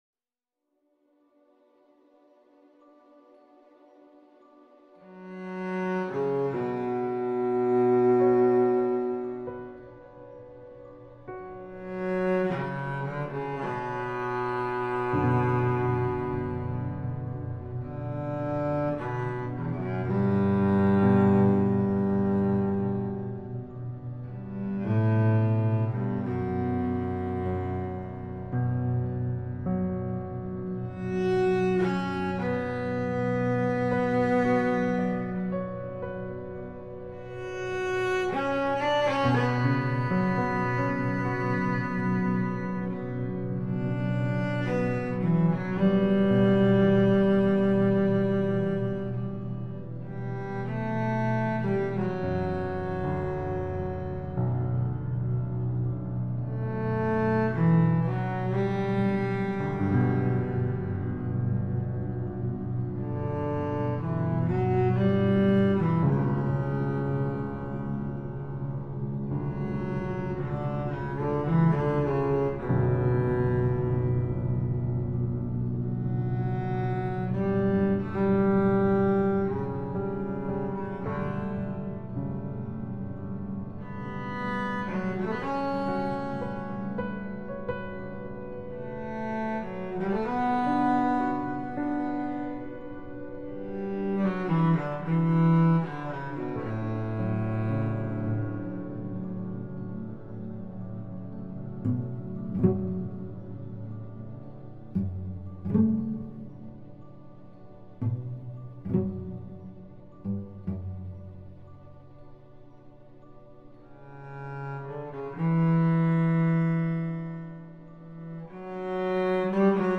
This was made with Cubase 7.5 and Kontakt 5.